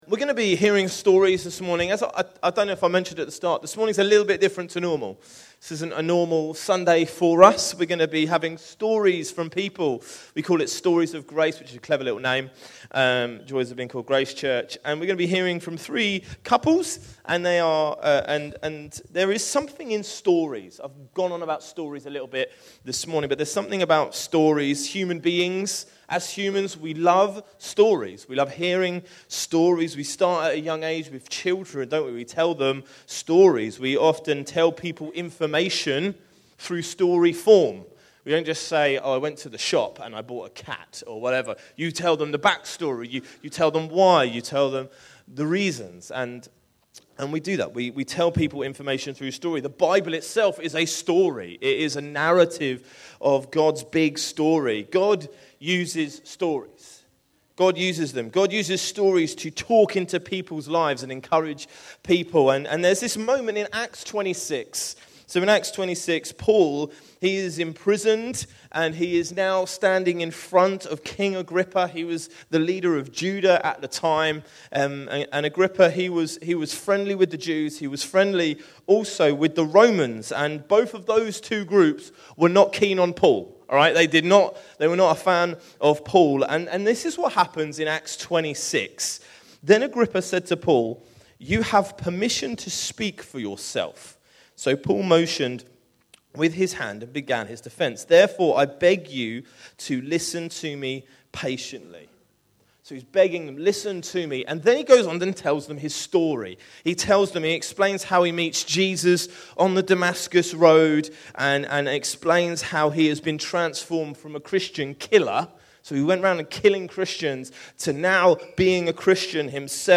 Other Sermons 2025